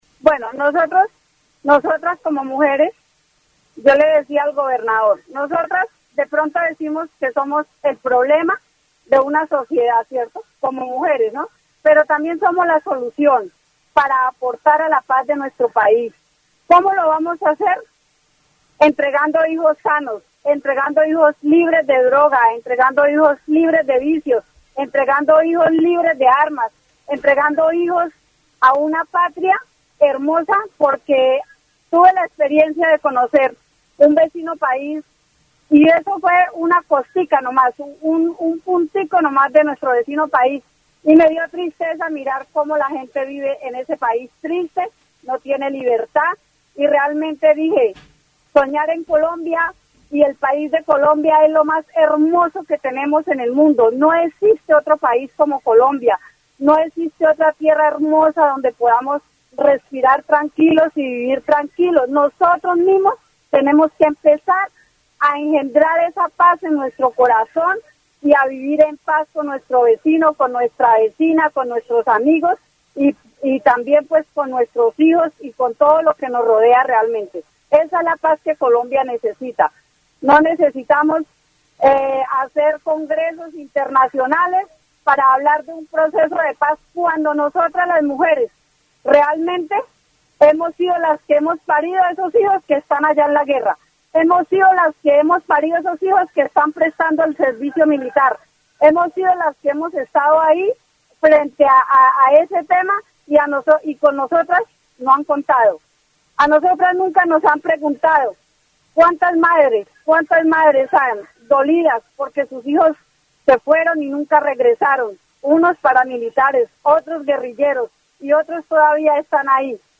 Vichada (Región, Colombia) -- Grabaciones sonoras , Programas de radio , Mujeres y construcción de paz en Colombia , Prevención de la violencia y el consumo de drogas en la infancia , Rol de la mujer en la transformación social , Educación para la convivencia y la armonía social